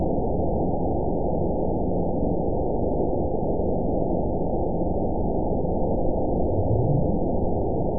event 912252 date 03/22/22 time 05:06:28 GMT (3 years, 1 month ago) score 9.15 location TSS-AB03 detected by nrw target species NRW annotations +NRW Spectrogram: Frequency (kHz) vs. Time (s) audio not available .wav